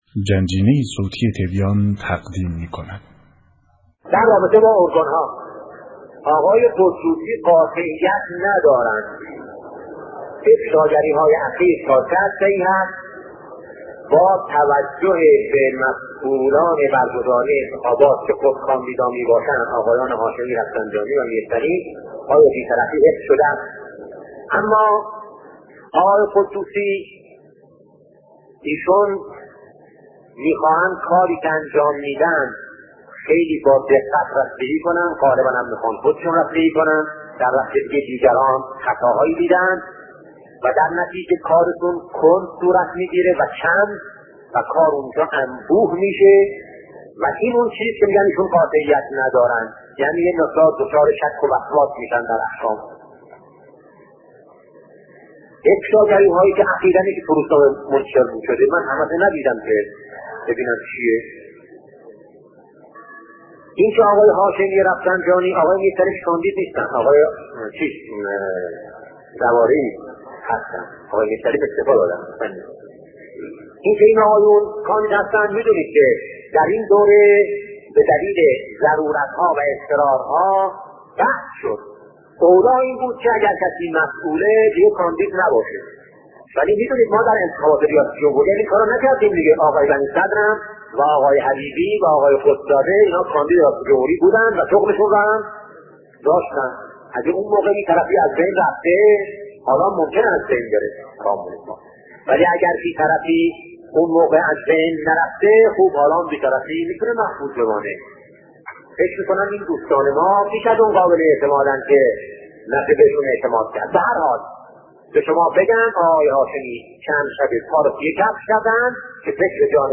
صوتی از شهید بهشتی، پرسش و پاسخ به سوالات مردم -بخش‌دوم